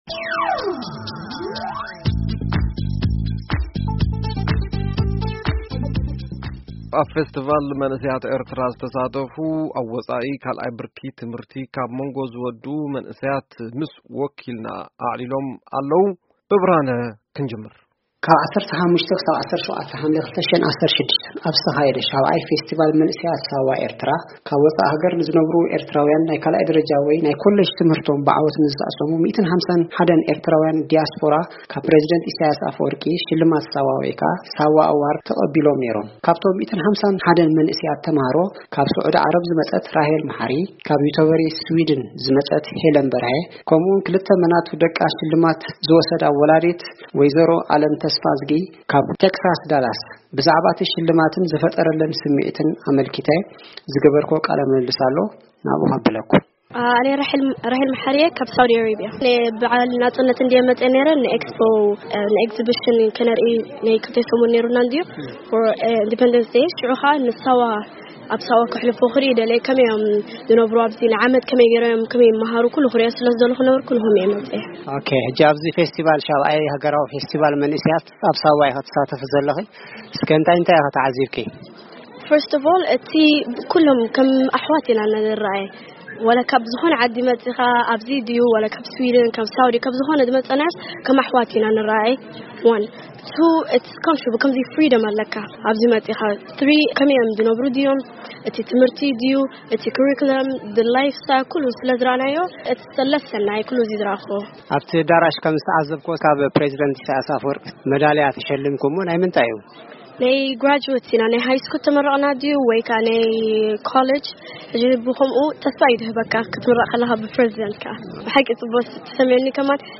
ምስ ወኪል ወኪል ቪኦኤ ዘዋግዑ ካብ ካንዳን ካብ ስዑዲ ዓረብን ዝመጹ መንእሰያት፡ ከምኡ ድማ ኣደ ካብ ከተማ ዳላስ ተክሳስ ዝኸዳ መናቱ ኣዋልድ፡ ብዛዕባቲ ፈስቲቫል ሓሳባቶም ገሊጾም ኣለዉ፡ ኣብ`ዚ ምስማዕ ይክኣል፡